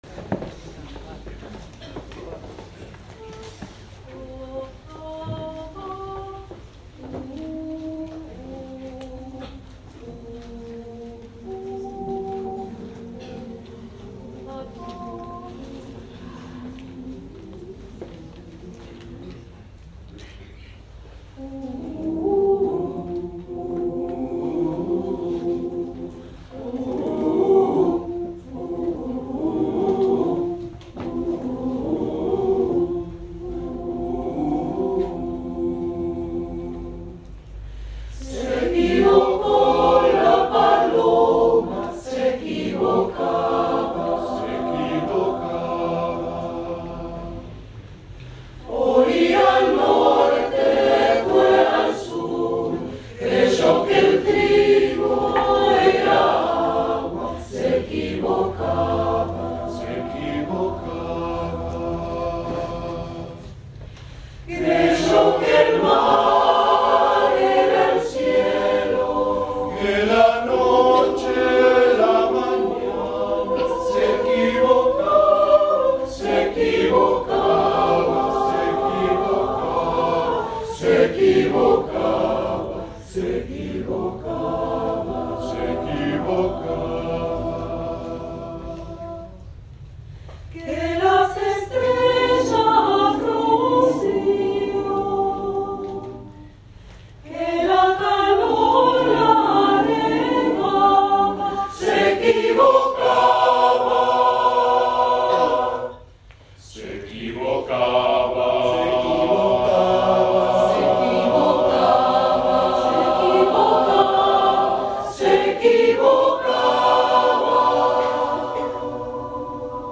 Cantamos en el TEATRO SAN JOSÉ